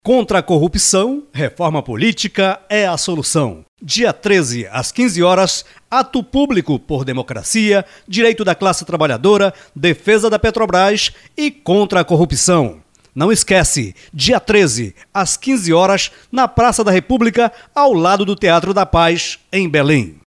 Spot de Rádio (1) - Dia Nacional de Luta - 13 de março de 2015